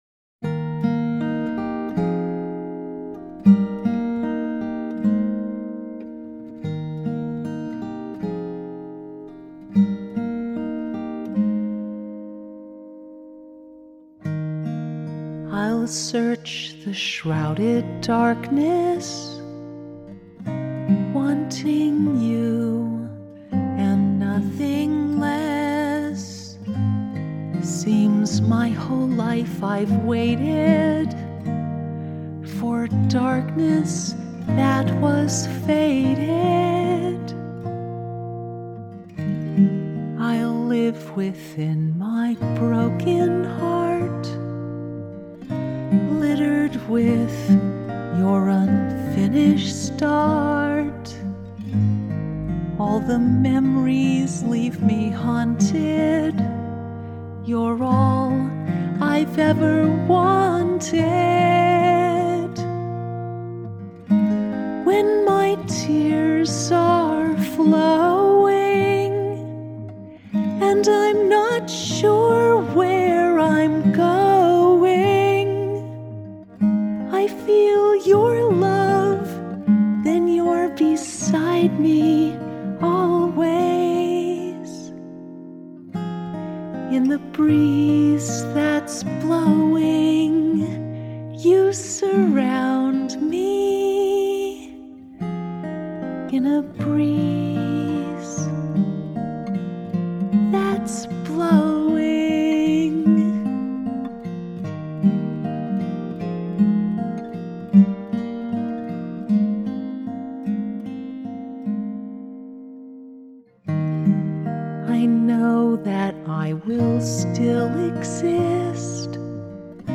Acoustic
beside-me-always-acoustic-5-11-18.mp3